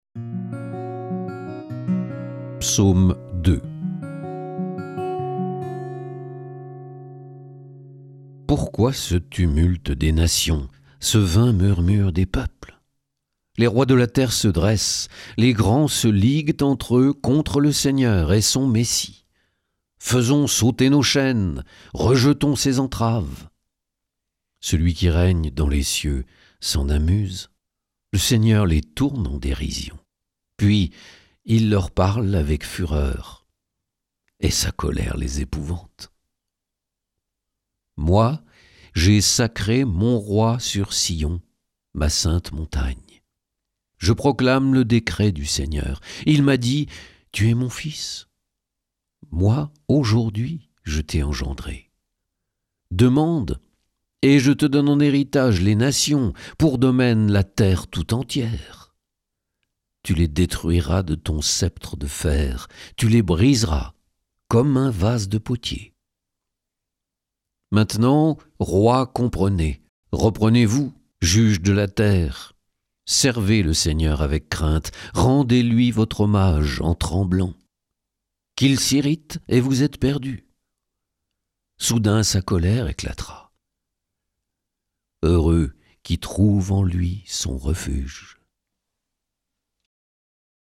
Et si on vous faisait la lecture des Psaumes ?
Quel choc de découvrir ou redécouvrir par la lecture ces textes que nous avons l’habitude d’entendre chantés ou psalmodiés !